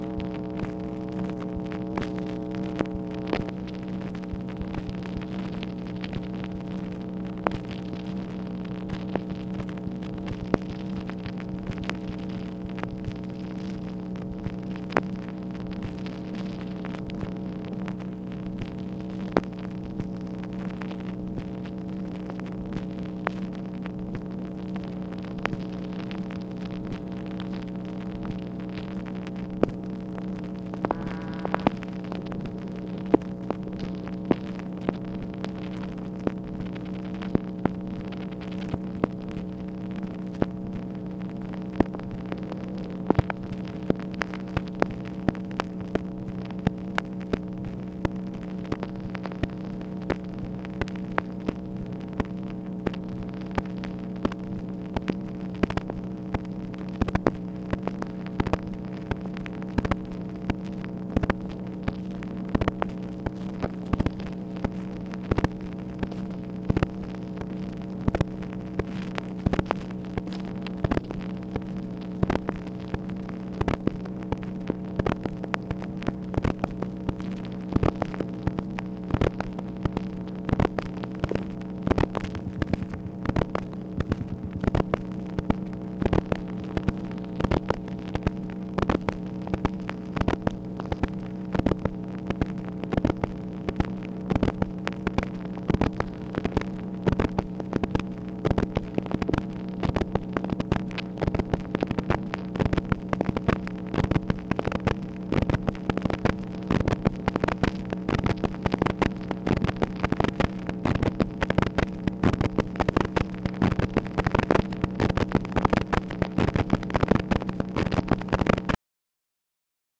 MACHINE NOISE, September 24, 1964
Secret White House Tapes | Lyndon B. Johnson Presidency